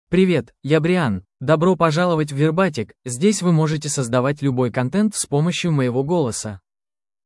Brian — Male Russian AI voice
Brian is a male AI voice for Russian (Russia).
Voice sample
Listen to Brian's male Russian voice.
Brian delivers clear pronunciation with authentic Russia Russian intonation, making your content sound professionally produced.